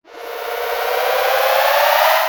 Teleport3.wav